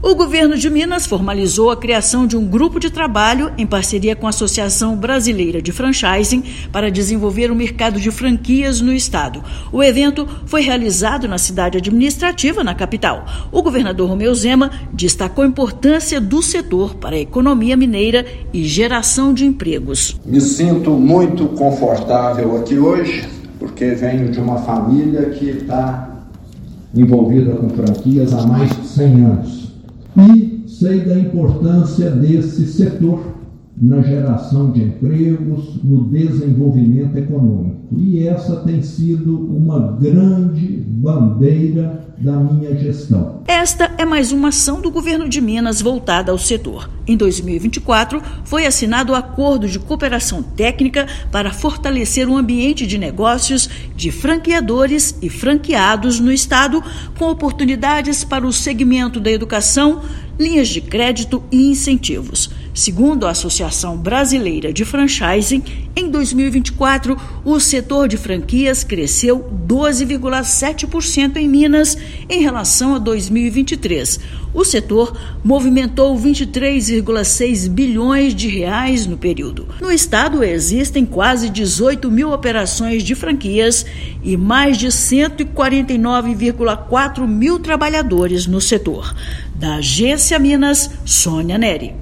Setor tem se fortalecido no estado e cresceu 12,7% em Minas Gerais frente ao ano anterior, com faturamento de R$ 23,6 bilhões. Ouça matéria de rádio.